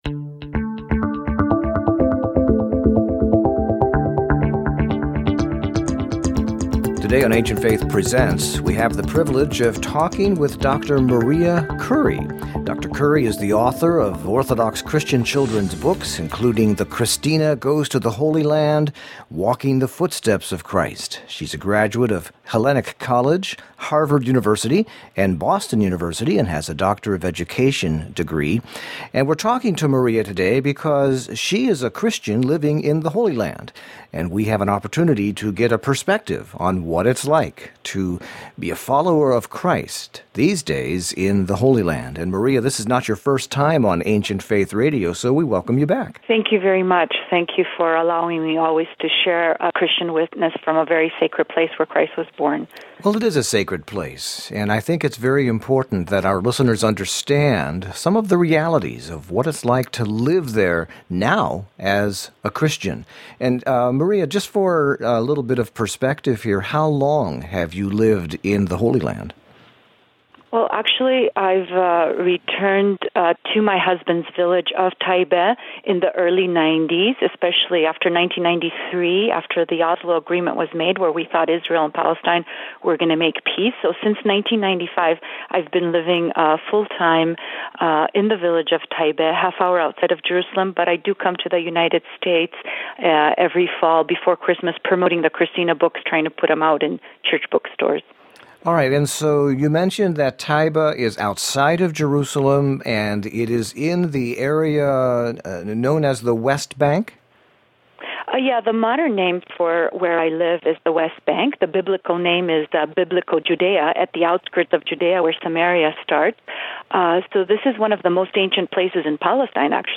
Audio (19 minutes, 17.2MB) Interview 4: A Christian on the West Bank, January 2014.